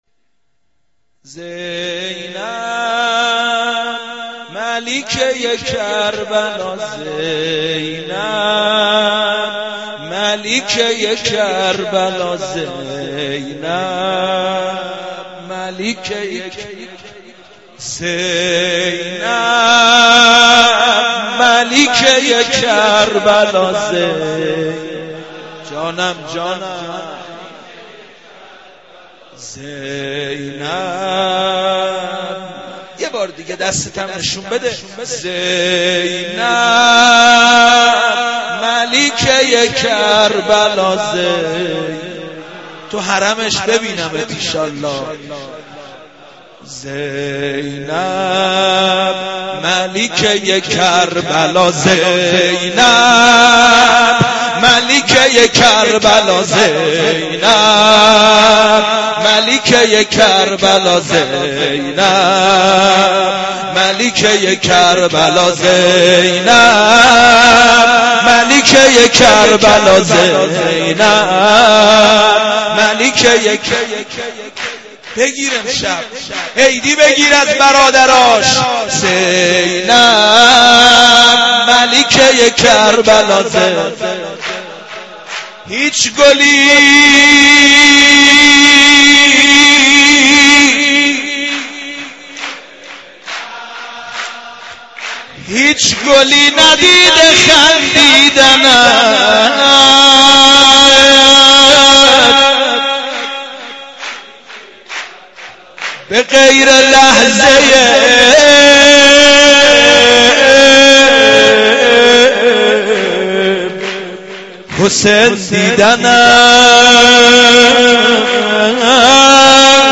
مولودی خوانی
به مناسبت میلا حضرت زینب(س)